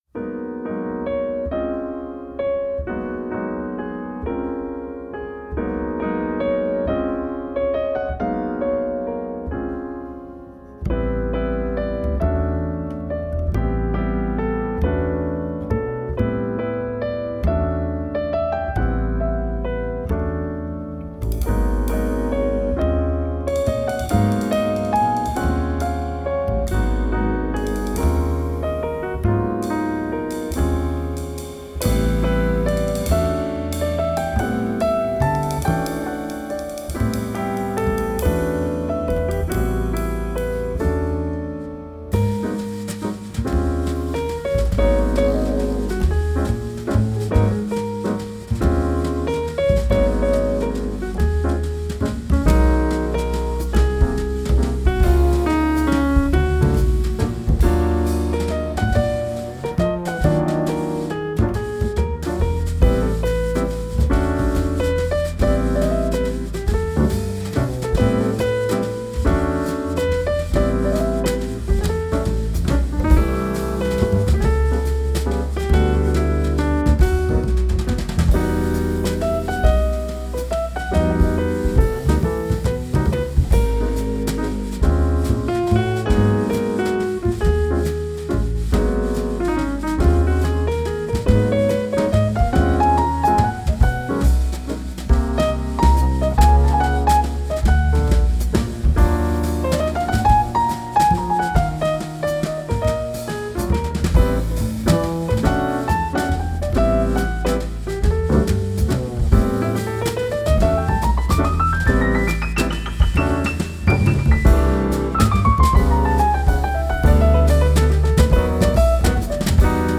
piano
double bass
drums